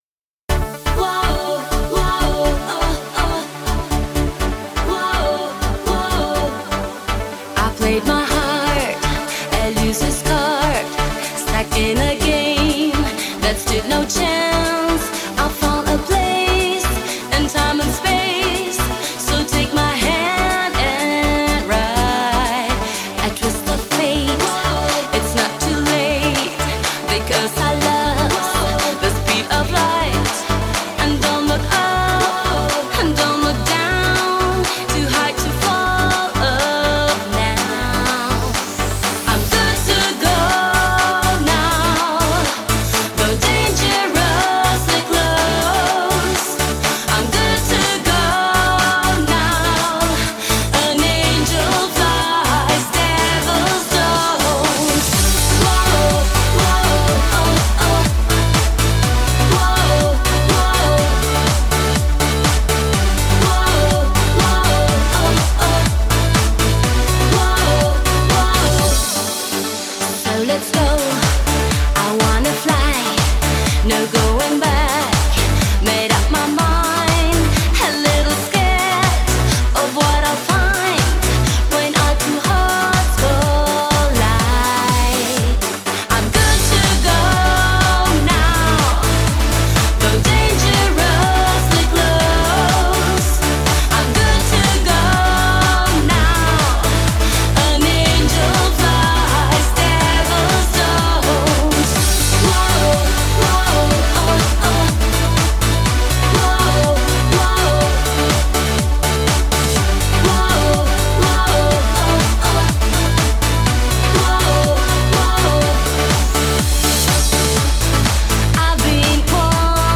Club-mix dance, pop edm music
club-mix , dance music , edm , pop music